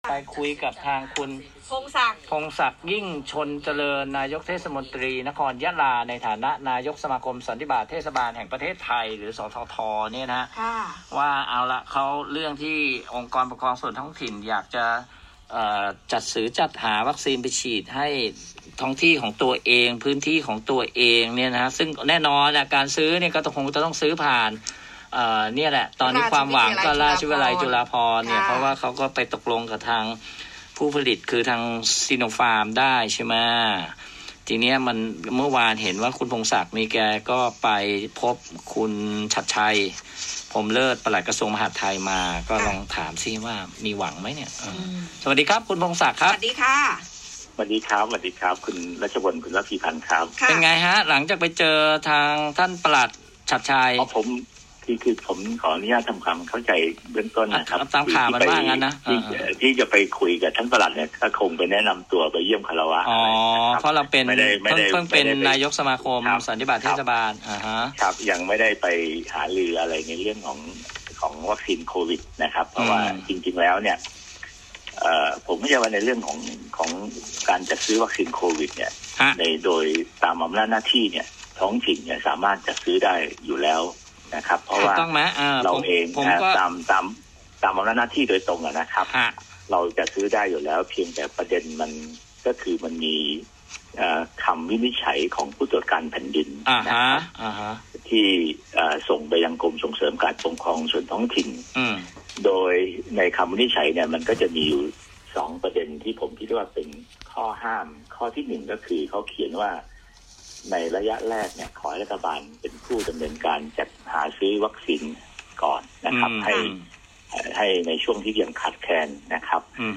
พงษ์ศักดิ์ ยิ่งชนม์เจริญ นายกเทศมนตรีนครยะลา ในฐานะนายกสมาคมสันนิบาตเทศบาลแห่งประเทศไทย (ส.ท.ท.) ให้สัมภาษณ์ในรายการ สนามข่าว 101 สถานีวิทยุ FM 101 กรณีองค์กรปกครองส่วนท้องถิ่น (อปท.) ต้องการจัดซื้อวัคซีนโควิด-19 ซิโนฟาร์ม